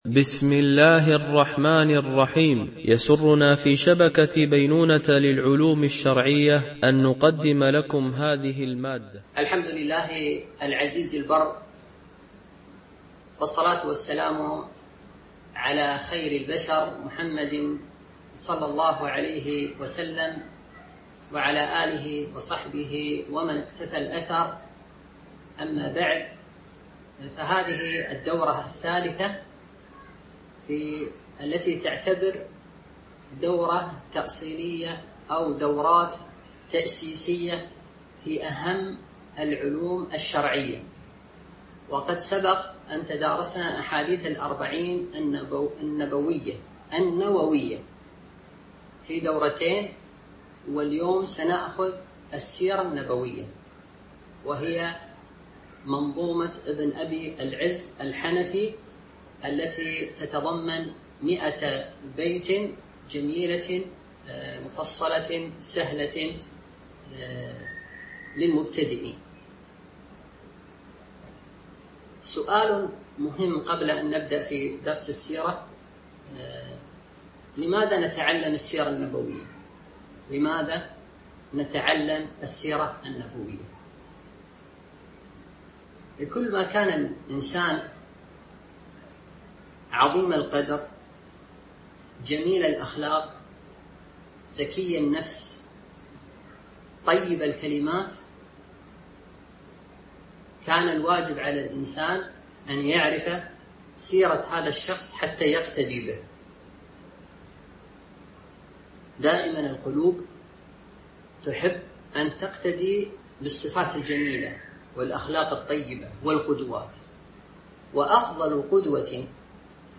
دورة علمية عن بعد في السيرة النبوية
التنسيق: MP3 Mono 22kHz 32Kbps (CBR)